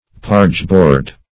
Pargeboard \Parge"board`\, n.